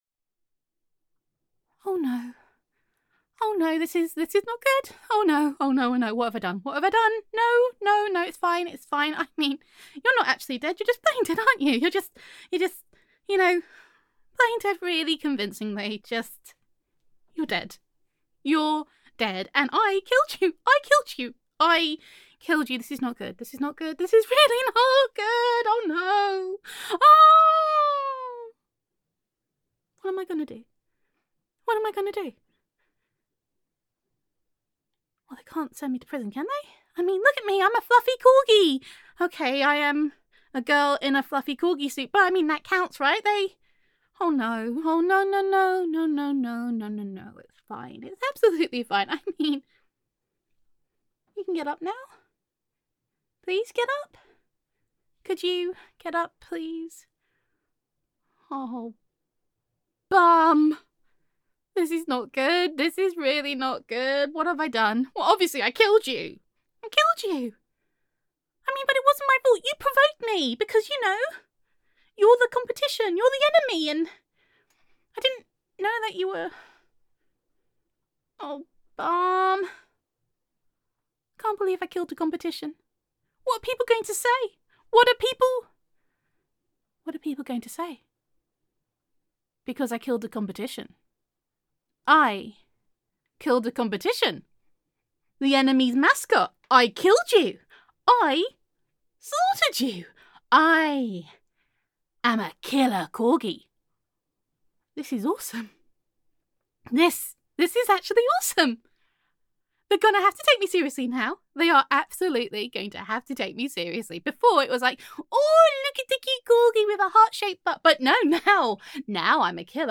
[F4A] I Did a Bad Thing [Corgi Mascot][No One Takes Corgis Seriously][Until They Become Killer Corgis][Accidental Death][Not So Accidental Death][Gender Neutral][A Hockey Team Mascot Has A Fortunate Accident the Second Time Around]